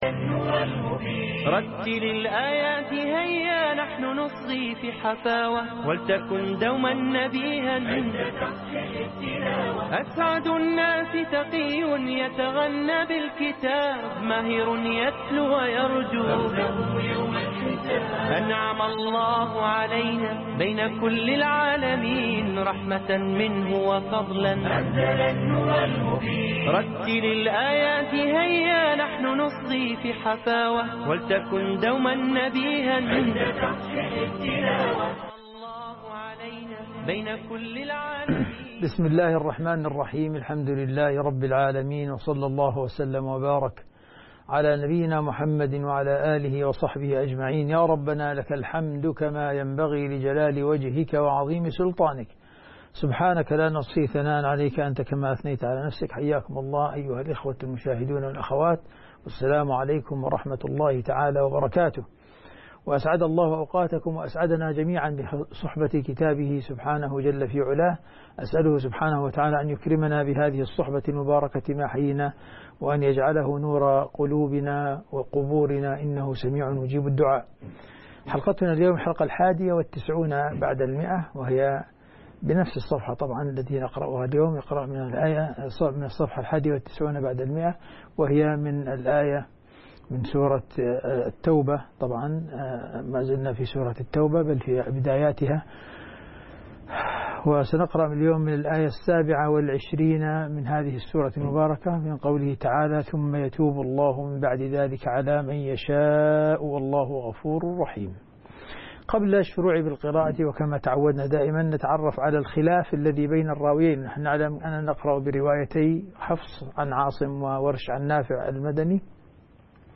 تصحيح تلاوة سورة التوبة من الآية 27